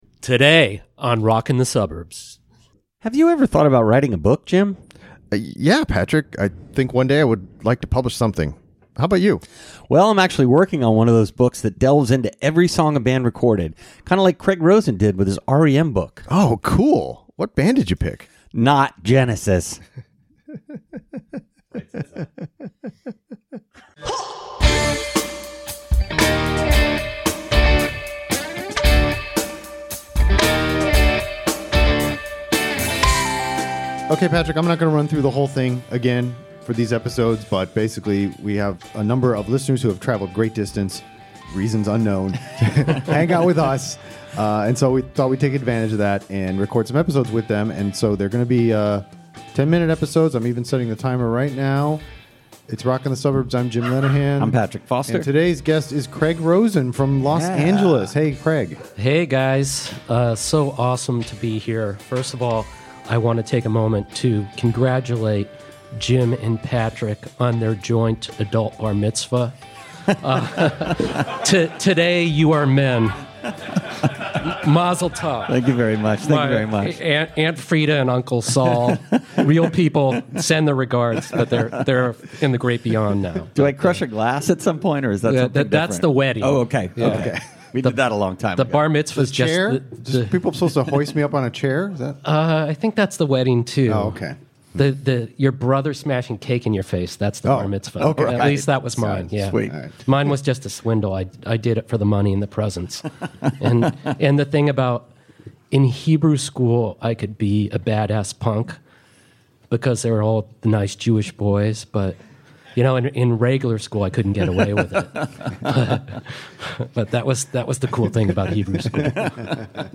Talkin' About R.E.M. - Live From Suburbs Fest